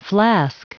Prononciation du mot flask en anglais (fichier audio)
Prononciation du mot : flask